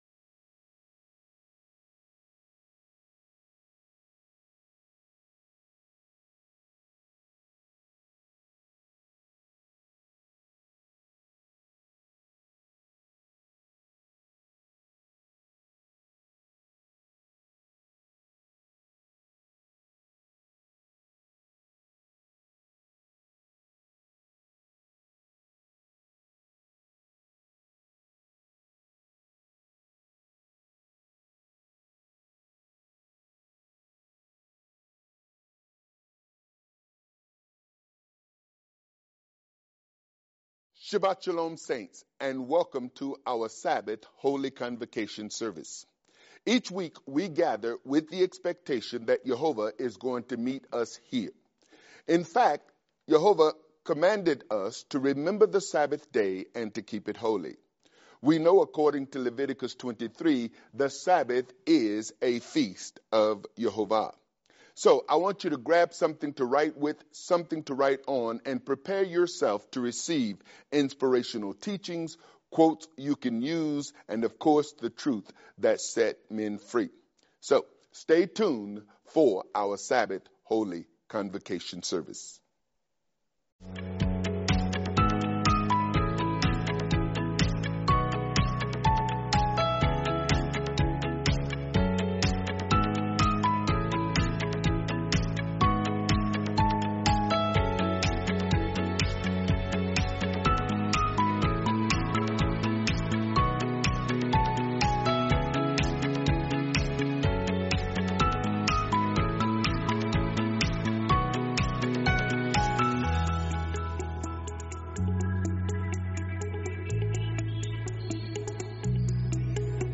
The Cost of Truth | Teaching